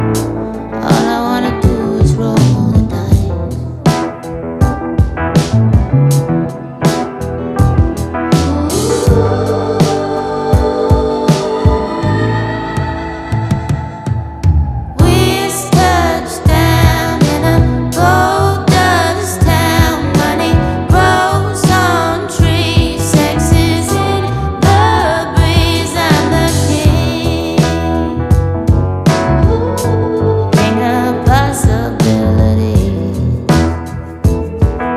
Alternative Pop
Жанр: Поп музыка / Альтернатива